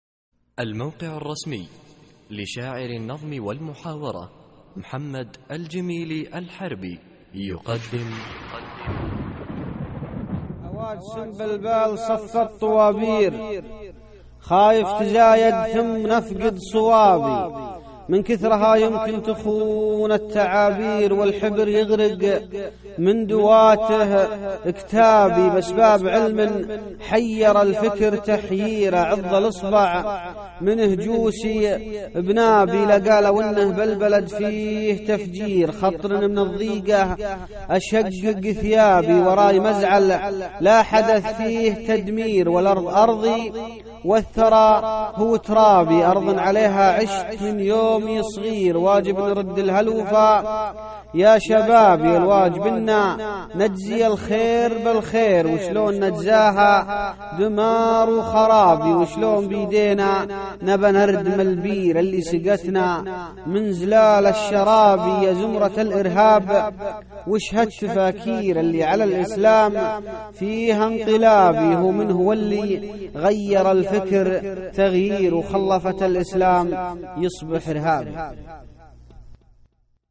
القصـائــد الصوتية
اسم القصيدة : الأرض أرضي ~ إلقاء